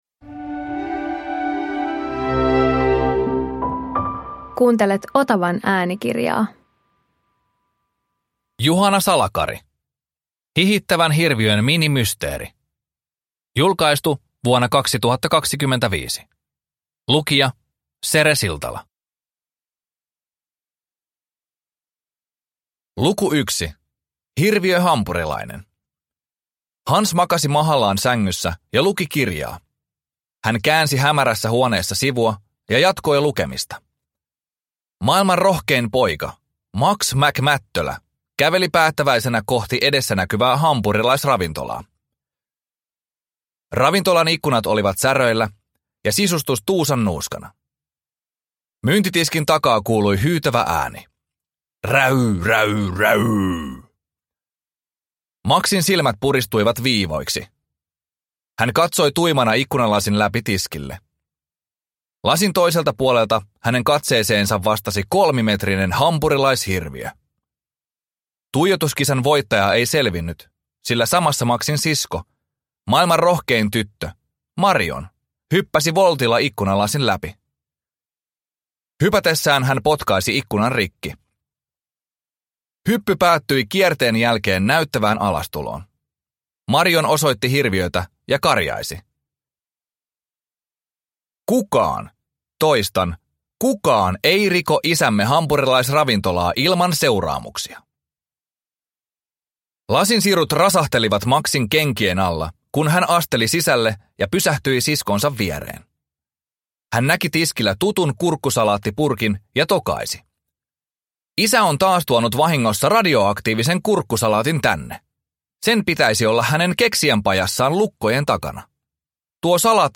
Hihittävän hirviön minimysteeri – Ljudbok